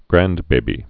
(grăndbābē, grăn-)